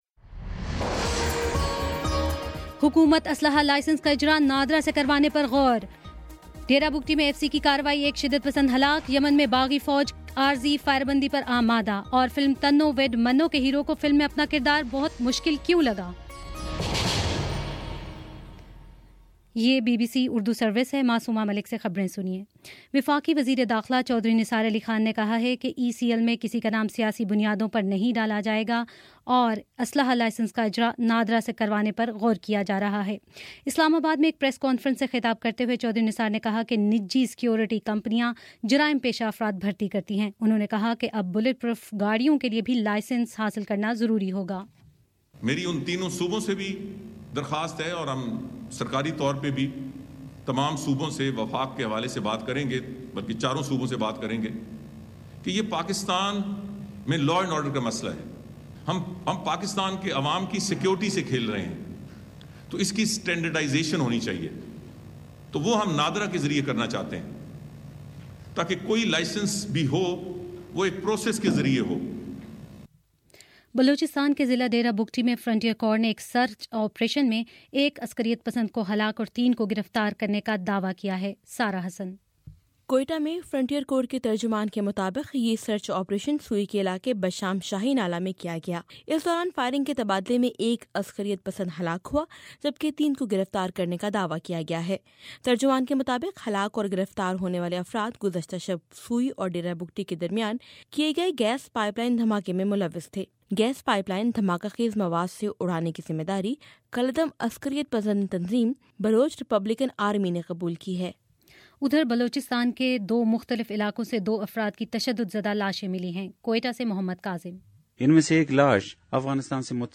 مئی 10: شام سات بجے کا نیوز بُلیٹن